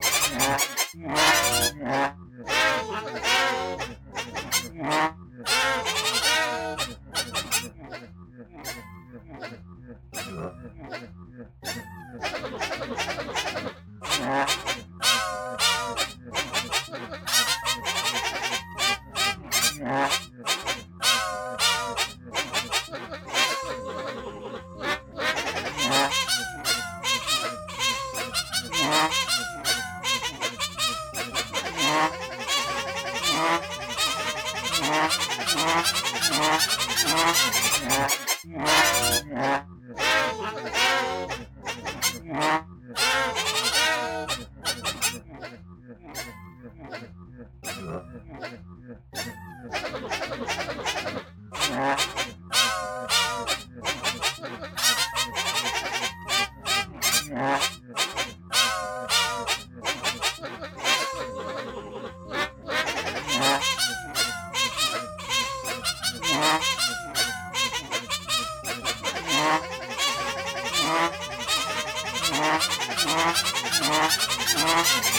Meow